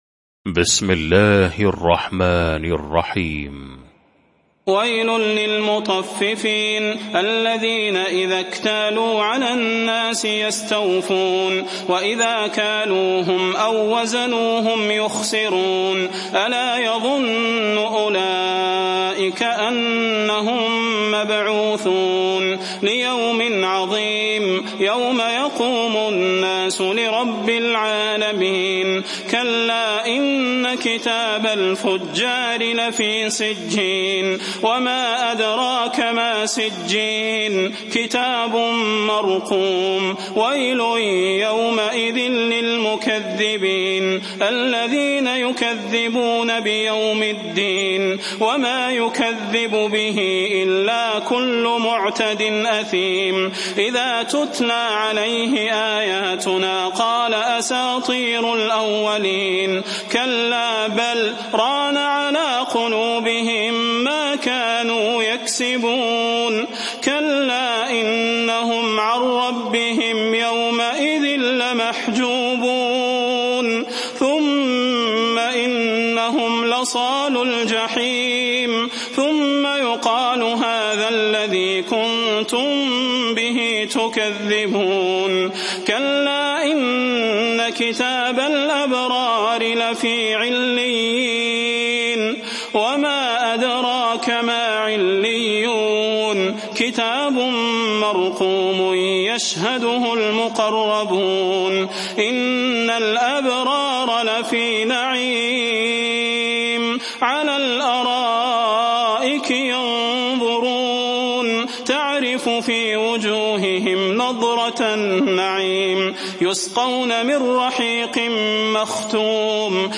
المكان: المسجد النبوي الشيخ: فضيلة الشيخ د. صلاح بن محمد البدير فضيلة الشيخ د. صلاح بن محمد البدير المطففين